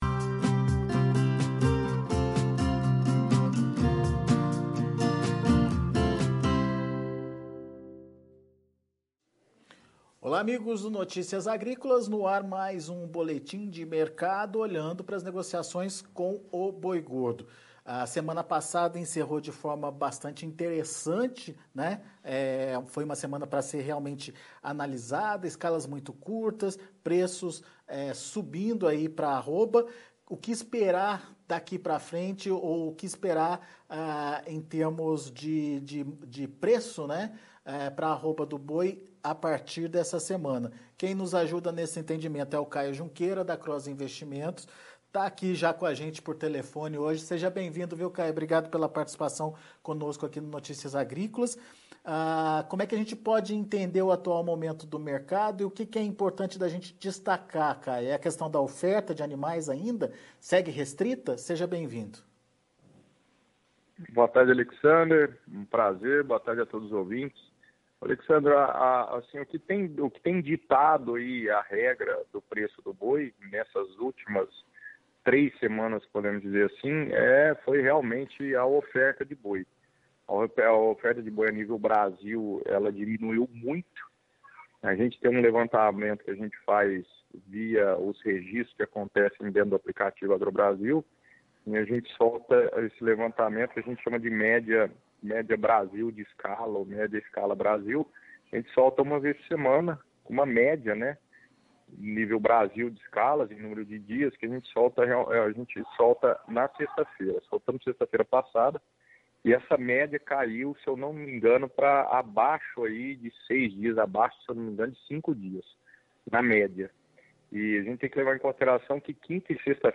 Em entrevista ao Notícias Agrícolas